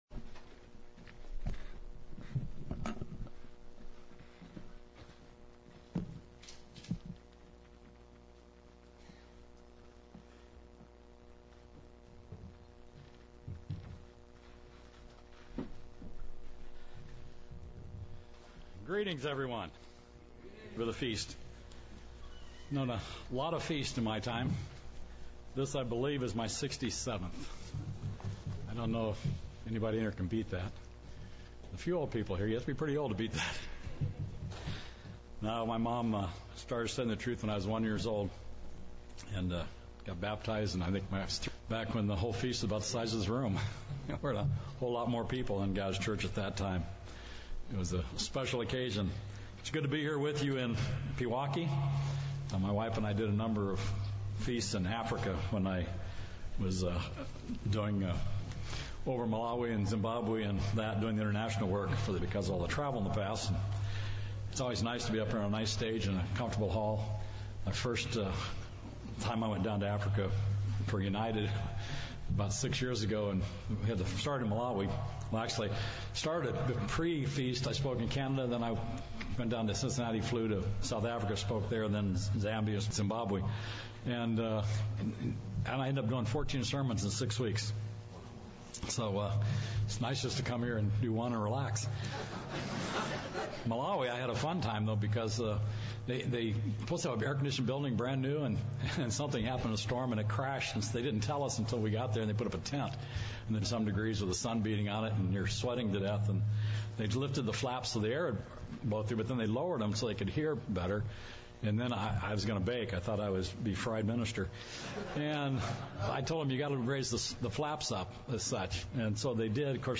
This sermon was given at the Pewaukee, Wisconsin 2022 Feast site.